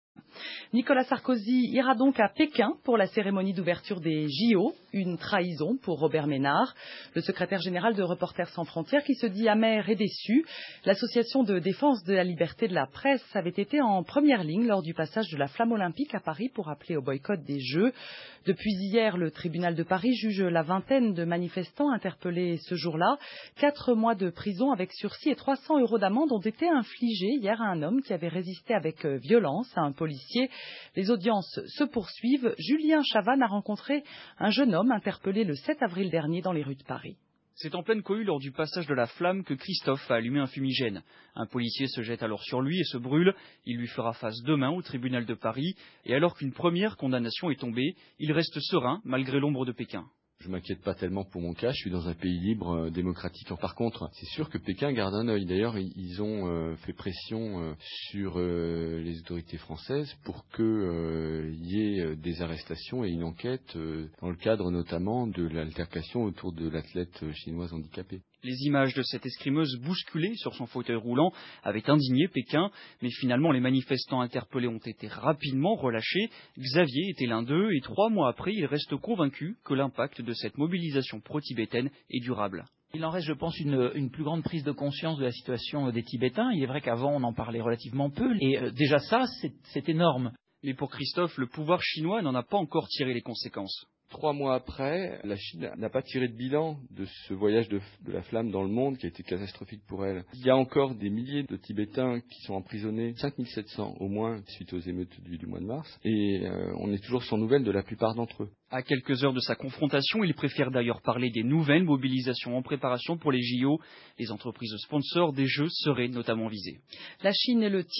Le reportage de France Inter le 9 Juillet 2008 (2 mn)